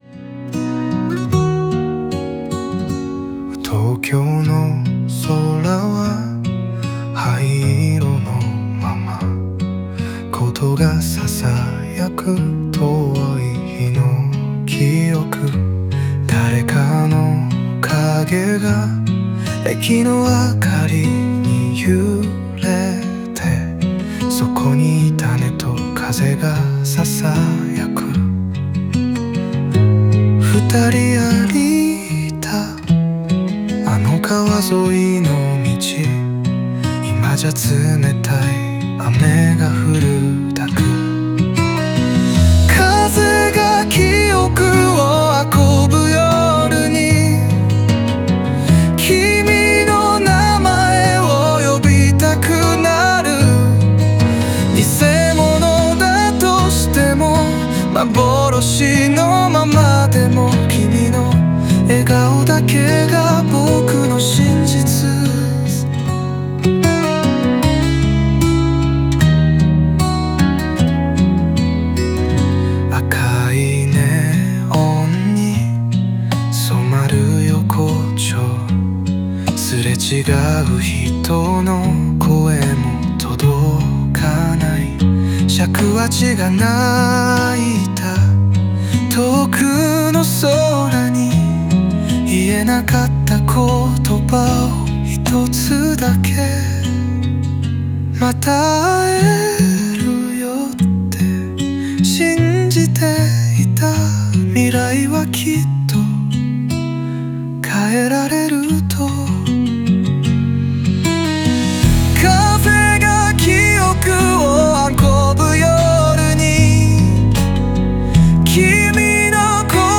オリジナル曲♪
尺八や箏の響きが、静かな都市の夜に溶け込み、記憶や幻影といった曖昧な存在が現実と交差します。